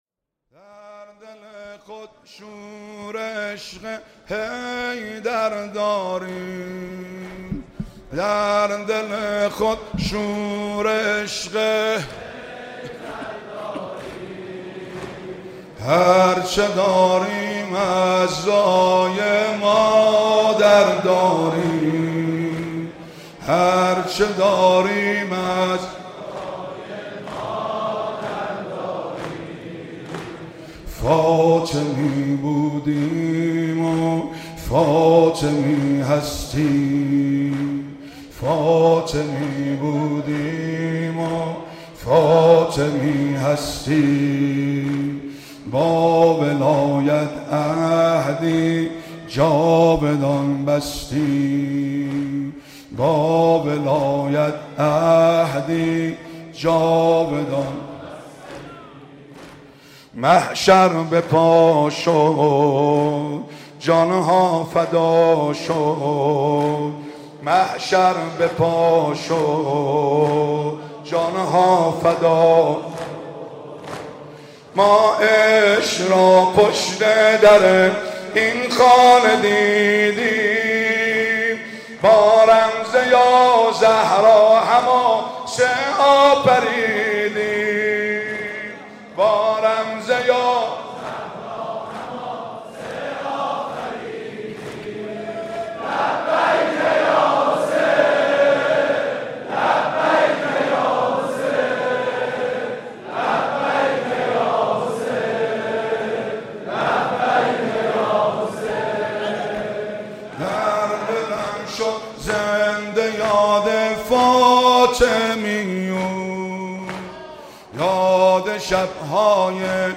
شب سوم فاطمیه98
زمینه _ در دل خود شور عشق حیدر داریم